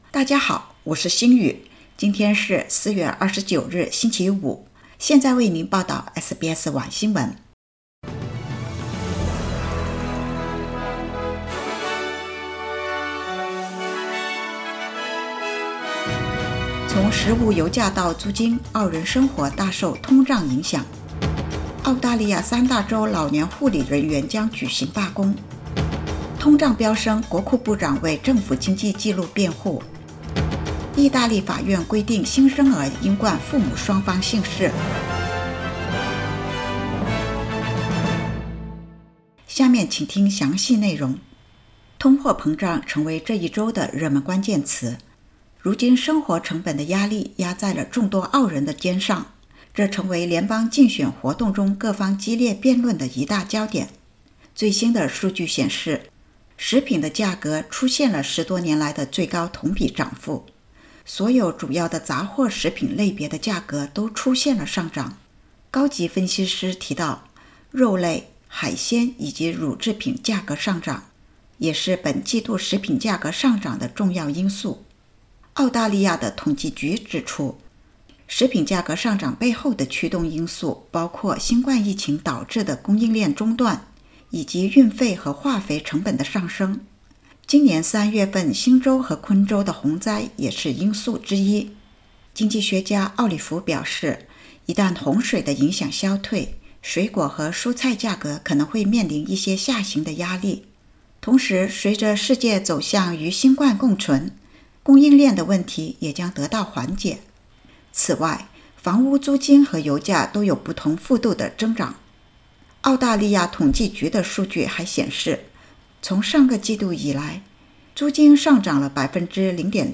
SBS晚新闻（2022年4月29日）